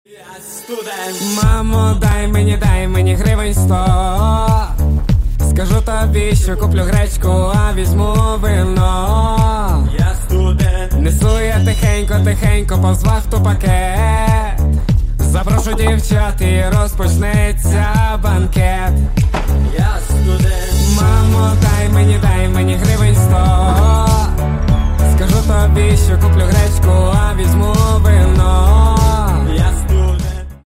Кавер И Пародийные Рингтоны